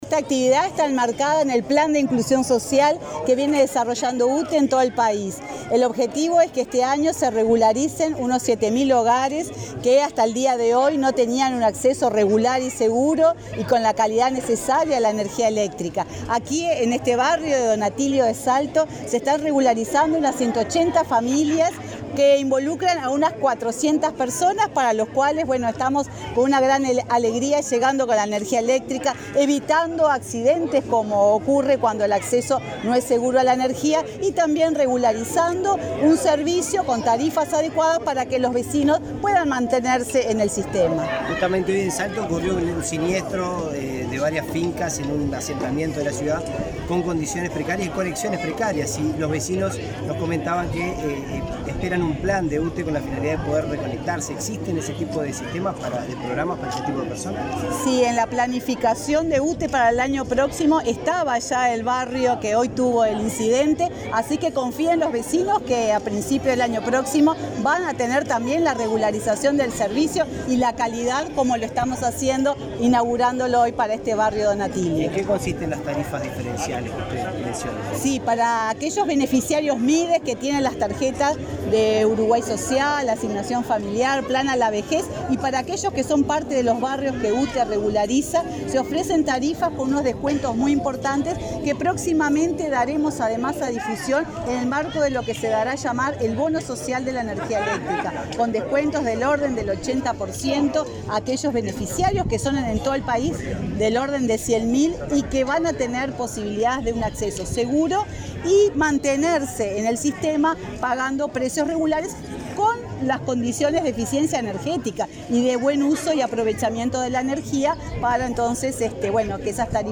Declaraciones de prensa de la presidenta de UTE, Silvia Emaldi
Tras el acto, la titular del ente efectuó declaraciones a la prensa.